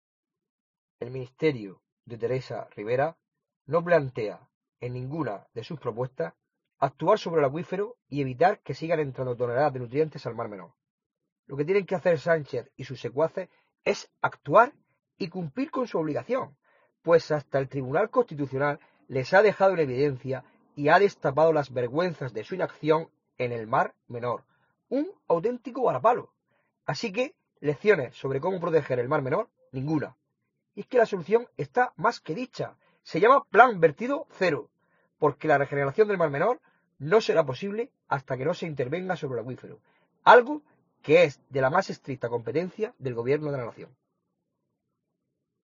Jesús Cano, diputado regional del Partido Popular